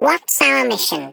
Sfx_tool_spypenguin_vo_enter_02.ogg